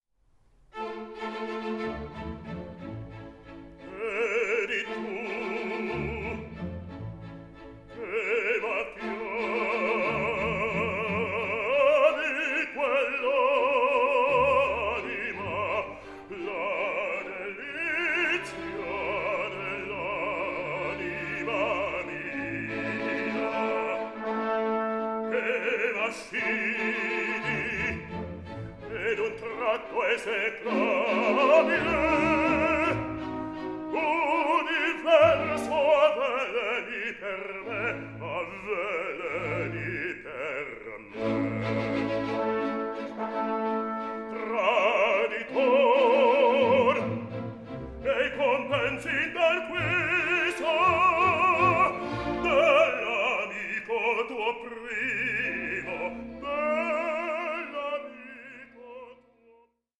resulting in a performance that is lively and balanced.